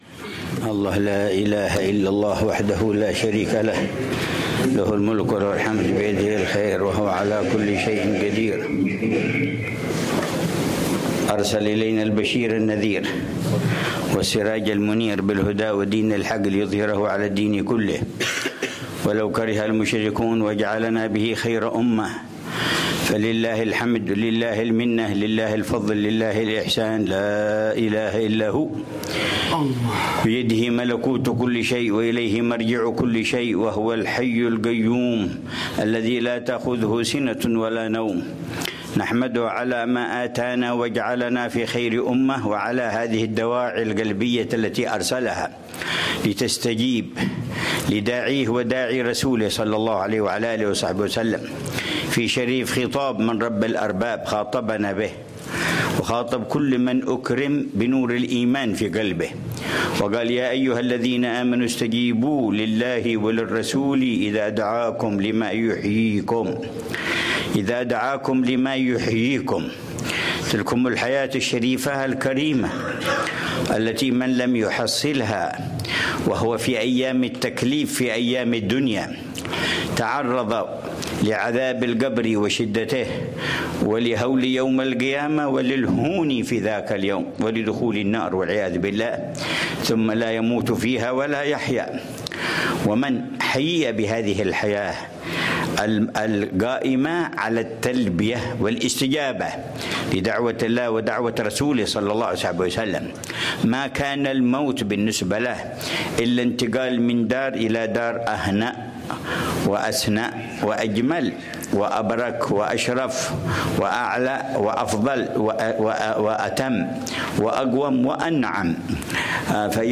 محاضرة
عبر اتصال مرئي